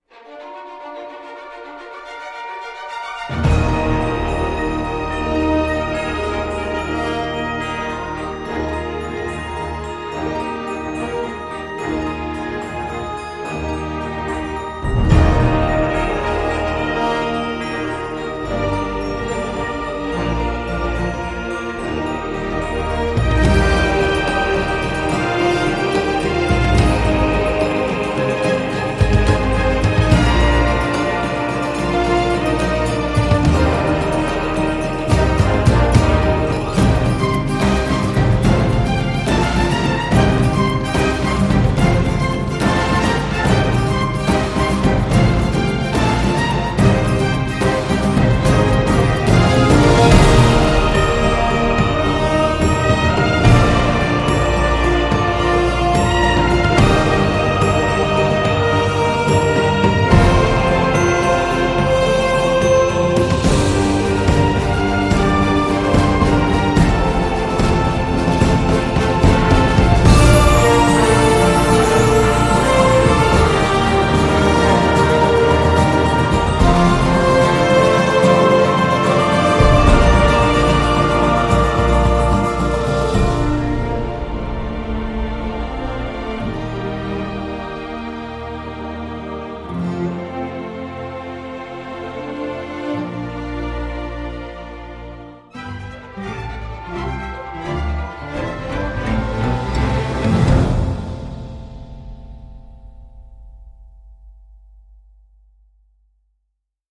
guitares électriques tonitruantes
Bruyant et fin comme du gros sel donc